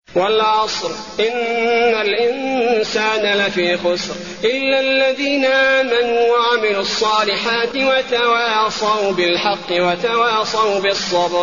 المكان: المسجد النبوي العصر